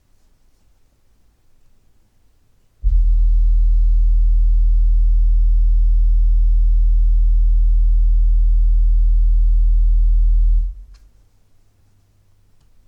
Ich hab mal ne Aufnahme mit einem 40Hz Testton angehängt.